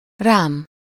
Ääntäminen
Synonyymit reám Ääntäminen Haettu sana löytyi näillä lähdekielillä: unkari Käännös Ääninäyte Pronominit 1. on me 2. of me 3. me US Esimerkit Ne szórd rám a homokot!